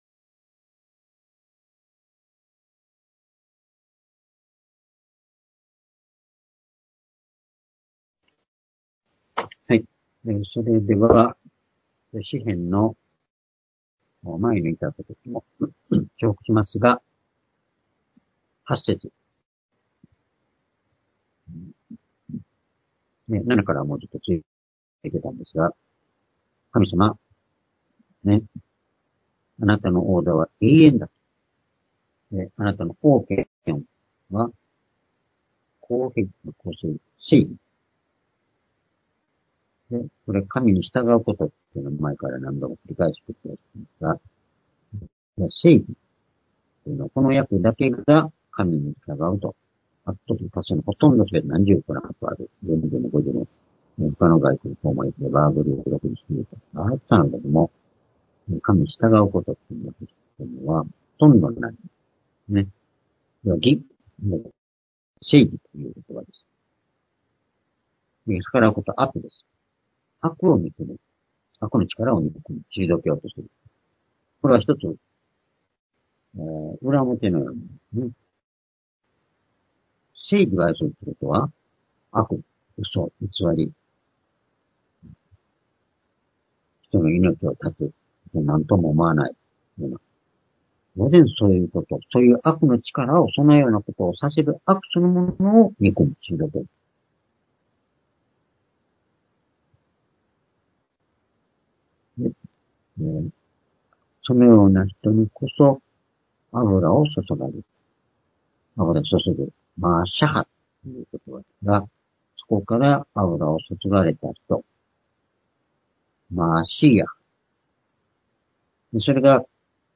（主日・夕拝）礼拝日時 ２０２４年11月19日（夕拝） 聖書講話箇所 「今日における見ること、聞くことの重要性」 詩編４５編８節～１１節 ※視聴できない場合は をクリックしてください。